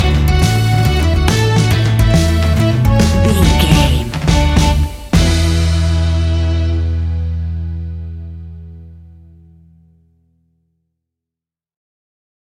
Uplifting
Ionian/Major
acoustic guitar
mandolin
drums
double bass
accordion